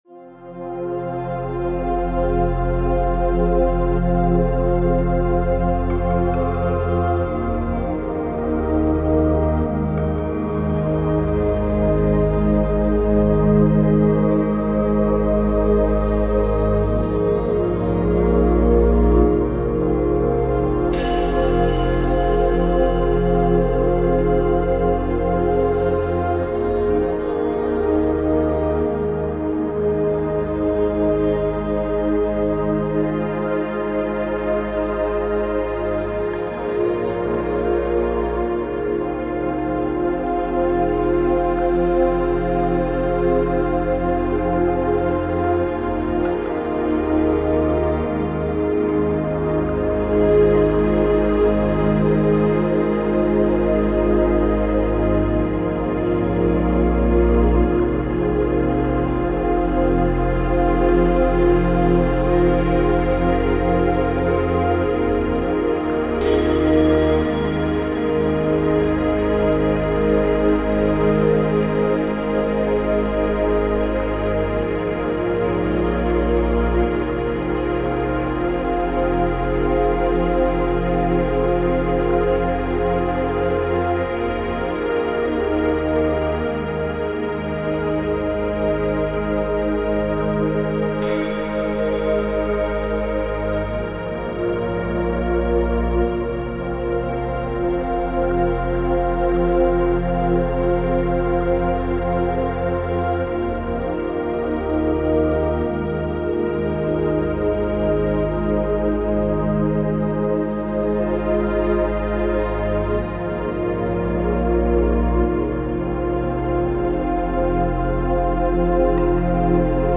417 Hz